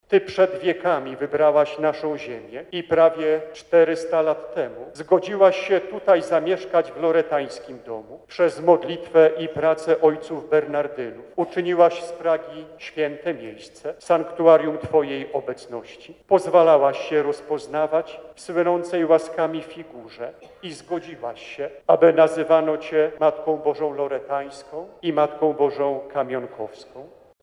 Zawsze budujmy nasze życie na skale którą jest Bóg – zachęcał biskup Marek Solarczyk w czasie nawiedzenia kopii Jasnogórskiego Wizerunku w sanktuarium Matki Bożej Loretańskiej.